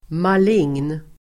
Ladda ner uttalet
malign adjektiv (om svulst, sjukdom etc), malignant [of tumours, disease, etc.]Uttal: [mal'ing:n] Böjningar: malignt, malignaSynonymer: elakartad, svårartadDefinition: elakartad